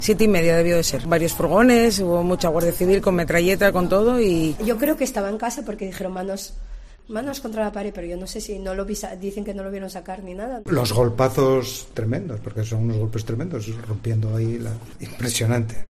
Vecinos de la calle Celestino Junquera de Gijón